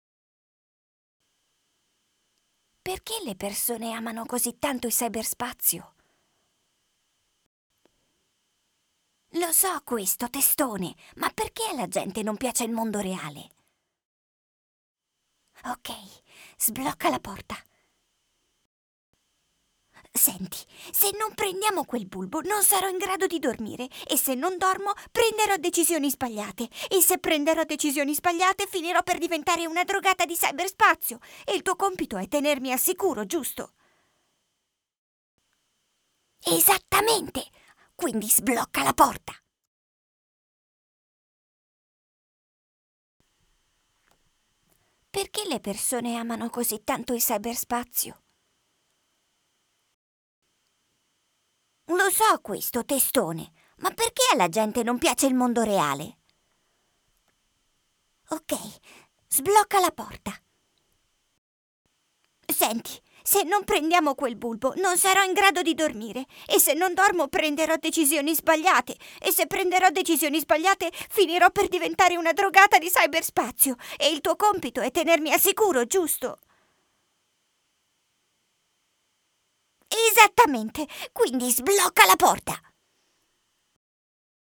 Female
Yng Adult (18-29), Adult (30-50)
Radio Commercials
All our voice actors have professional broadcast quality recording studios.